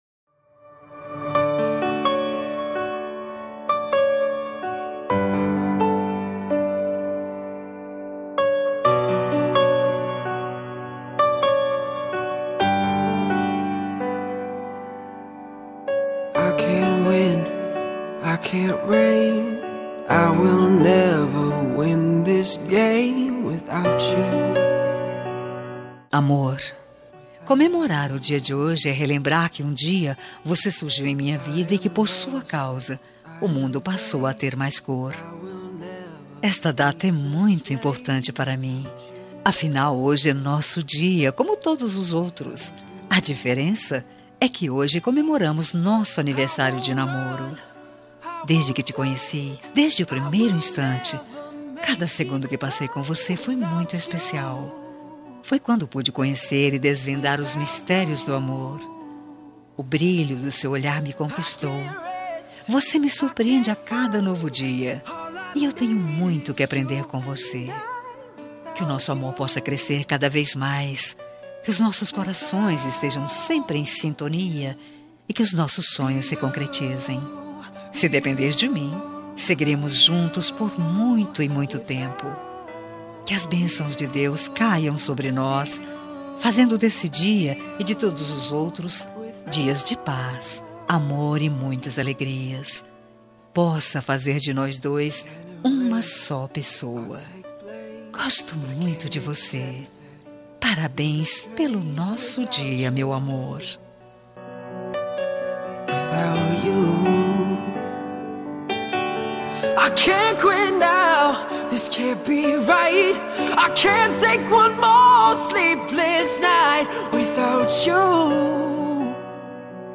Telemensagem Aniversário de Namoro – Voz Feminina – Cód: 8095 – Linda.
8095-aniv-namoro-fem.m4a